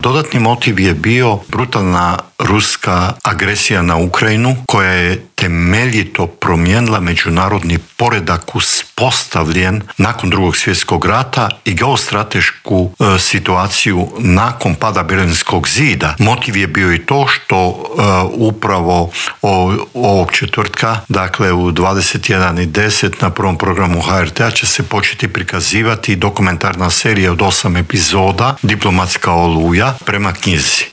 ZAGREB - Povodom drugog izdanja knjige ‘Diplomatska oluja - sjećanja najdugovječnijeg Tuđmanovog ministra‘, u Intervjuu tjedna Media servisa gostovao je bivši ministar vanjskih poslova i posebni savjetnik premijera Mate Granić.